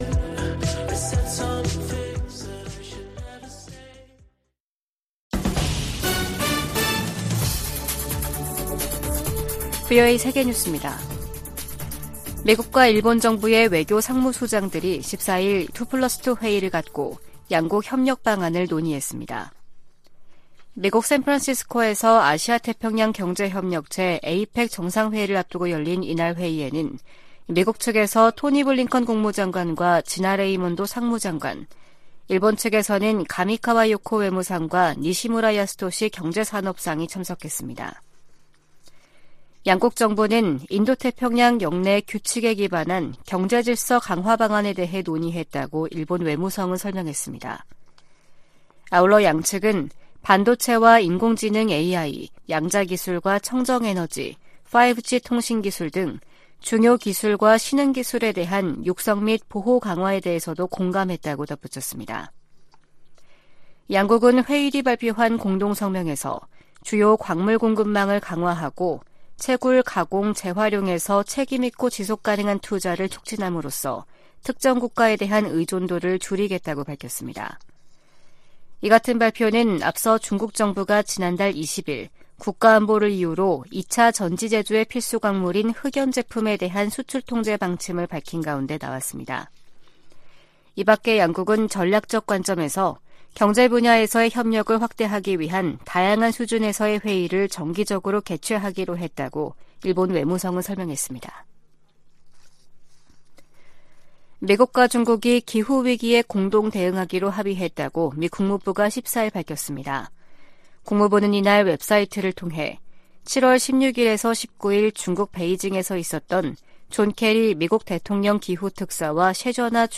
VOA 한국어 아침 뉴스 프로그램 '워싱턴 뉴스 광장' 2023년 11월 16일 방송입니다. 미국과 한국, 일본의 외교수장들이 미국에서 만나 중동 정세, 북한의 러시아 지원, 경제 협력 확대 등을 논의했습니다. 백악관은 조 바이든 대통령이 미중 정상회담과 관련해 대결과 외교 모두 두려워하지 않을 것이라고 밝혔습니다. 북한은 신형 중거리 탄도미사일, IRBM에 사용할 고체연료 엔진 시험을 성공적으로 진행했다고 밝혔습니다.